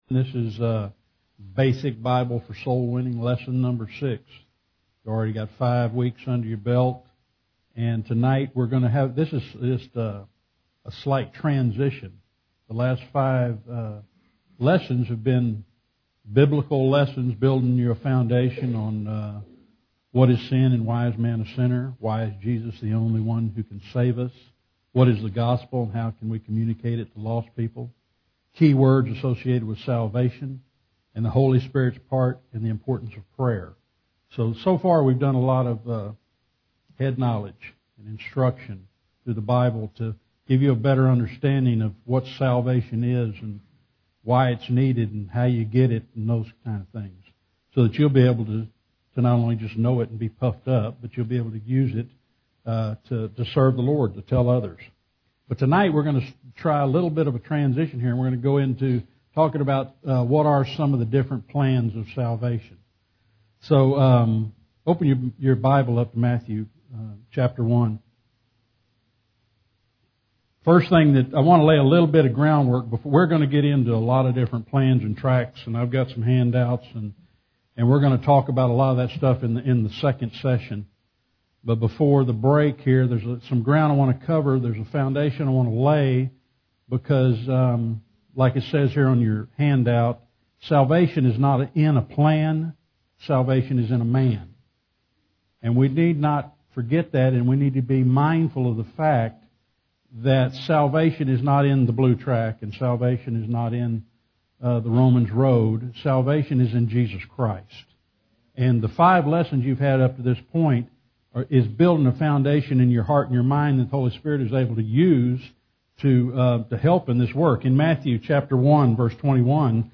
Soul Winning Lesson #6